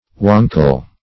Search Result for " wankle" : The Collaborative International Dictionary of English v.0.48: Wankle \Wan"kle\, a. [AS. wancol.] Not to be depended on; weak; unstable.
wankle.mp3